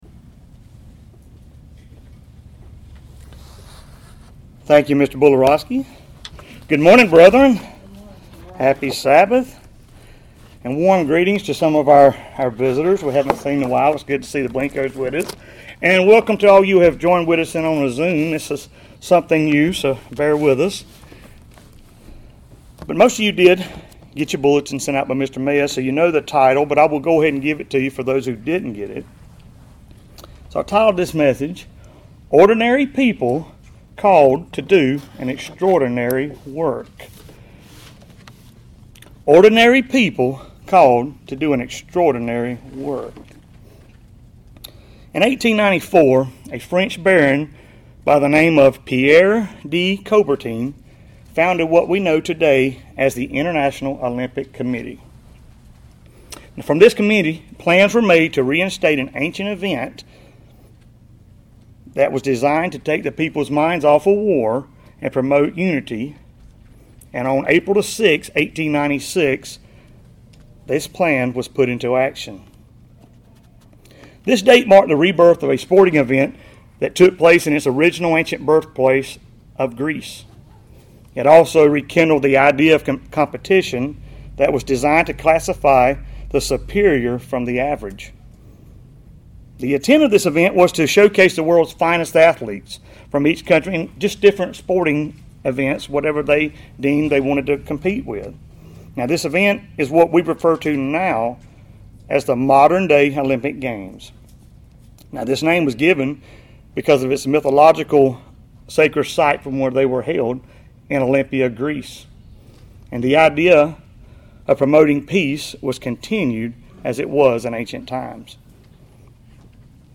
Given in Columbus, GA Central Georgia